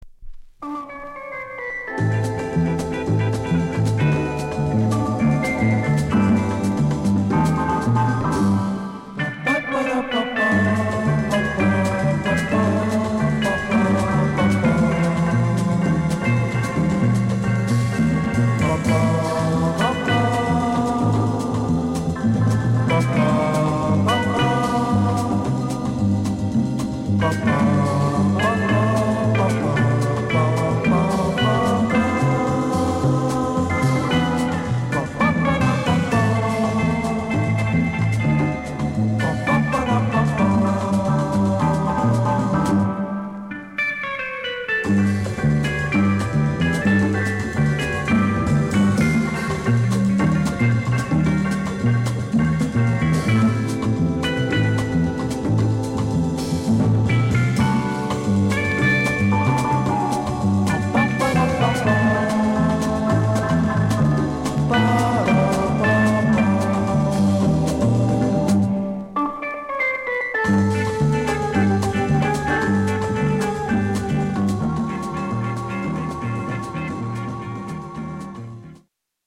(60年代のブラジル盤はコンディション完璧な物を見つけるのは難しいので曲間等の多少のチリノイズは予めご了承ください。)